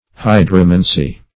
Search Result for " hydromancy" : Wordnet 3.0 NOUN (1) 1. divination by water (as by patterns seen in the ebb and flow of the tides) ; The Collaborative International Dictionary of English v.0.48: Hydromancy \Hy"dro*man`cy\, n. [Hydro-, 1 + -mancy: cf. F. hydromancie.]
hydromancy.mp3